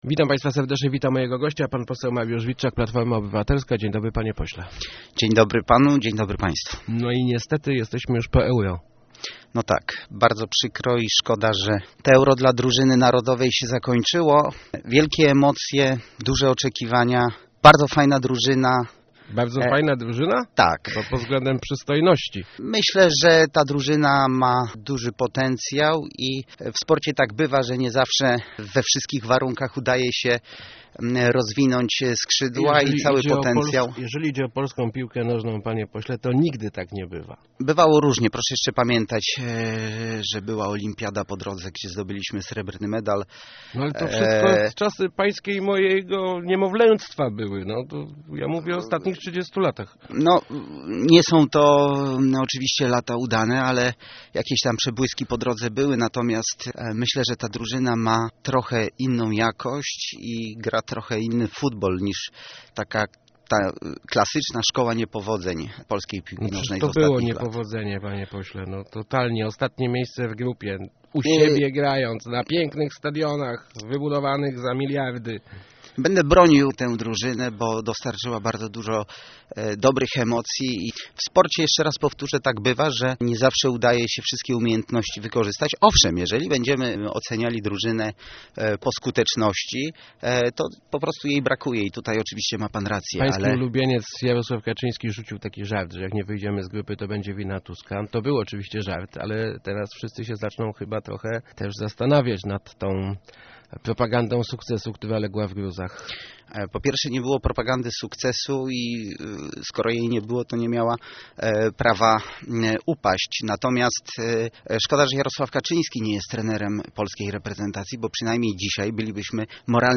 msw06.jpgEURO 2012 przejdzie do historii jako pewien punkt zwrotny, moment zakończenia transformacji systemowej - mówi w Rozmowach Elki poseł PO Mariusz Witczak. Jego zdaniem należy docenić przede wszytskim modernizację infrastruktury i międzynarodową promocję Polski.